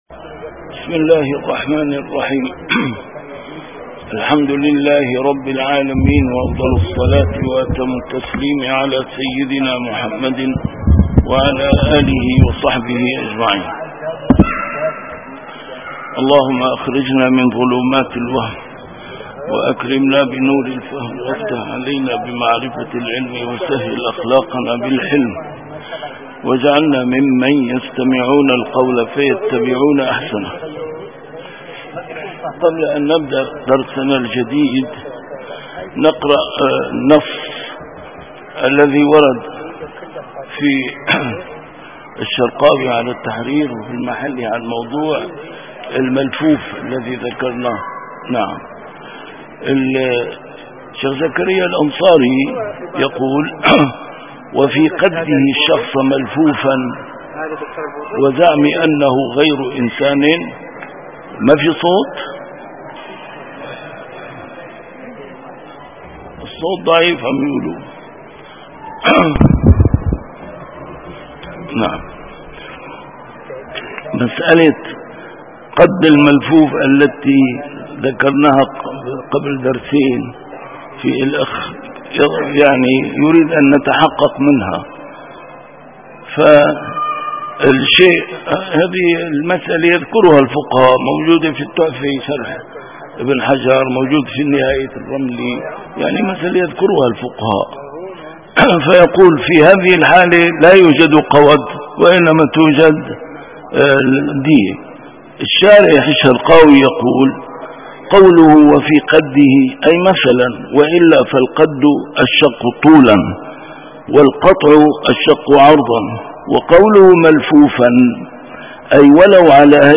A MARTYR SCHOLAR: IMAM MUHAMMAD SAEED RAMADAN AL-BOUTI - الدروس العلمية - كتاب الأشباه والنظائر للإمام السيوطي - كتاب الأشباه والنظائر، الدرس الرابع والأربعون: فوائد نختم بها الكلام على هذه القاعدة